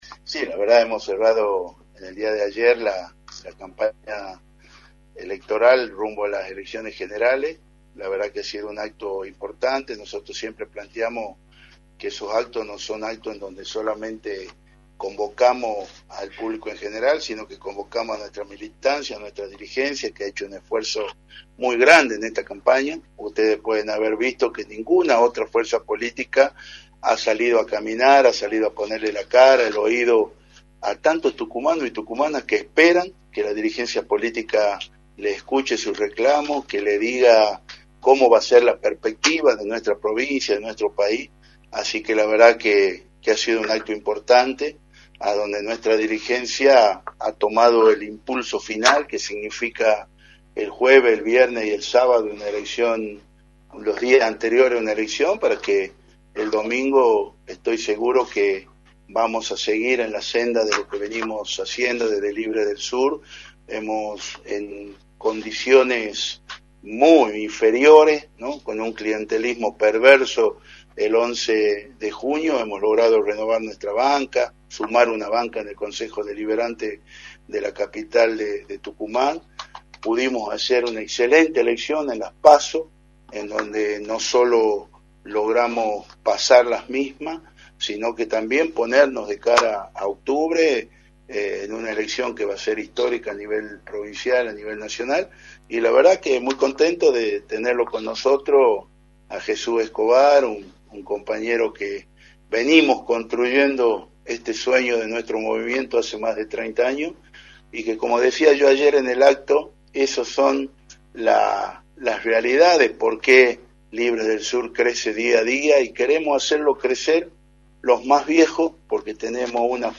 Federico Masso, Legislador y candidato a Diputado Nacional, analizó en Radio del Plata Tucumán, por la 93.9,  las repercusiones del cierre de su campaña y la situación política del país.